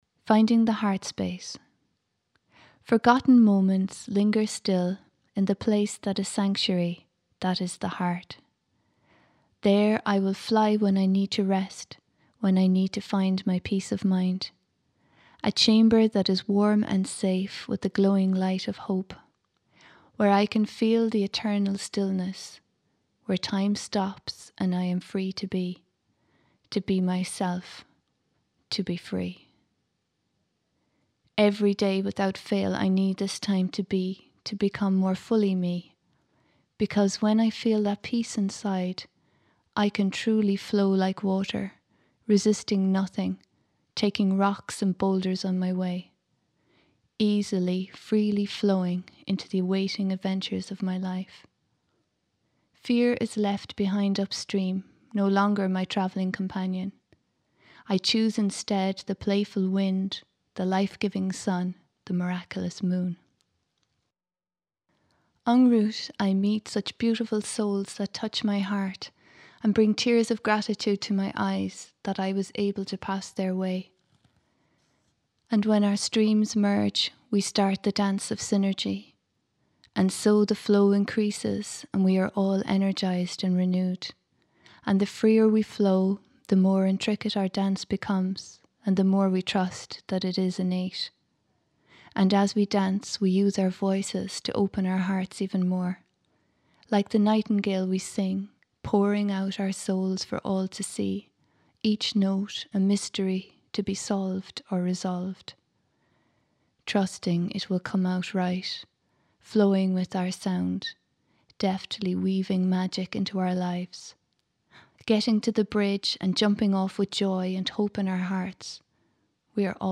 You can listen to the full poem using the link below.